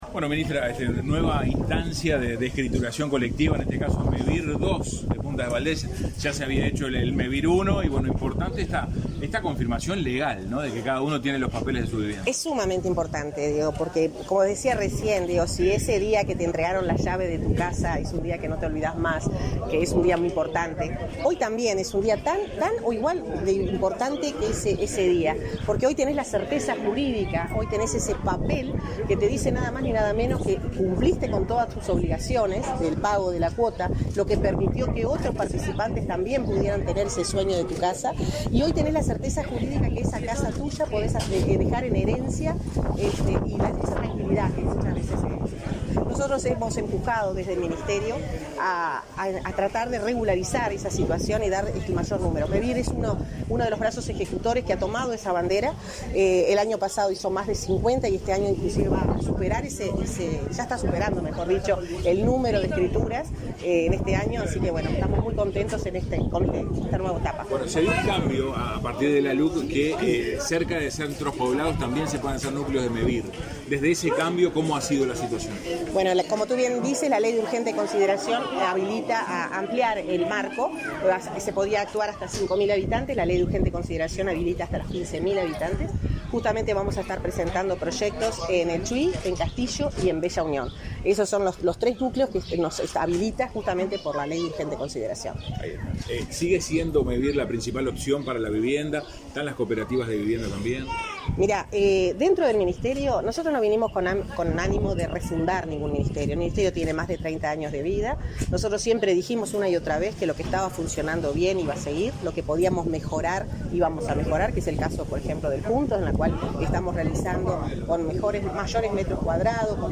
Declaraciones a la prensa de la ministra de Vivienda y Ordenamiento Territorial, Irene Moreira
Declaraciones a la prensa de la ministra de Vivienda y Ordenamiento Territorial, Irene Moreira 21/12/2022 Compartir Facebook X Copiar enlace WhatsApp LinkedIn Tras participar en el acto de escrituras de 60 viviendas en la localidad de Puntas de Valdez, departamento de San José, este 20 de diciembre, la ministra Irene Moreira realizó declaraciones a la prensa.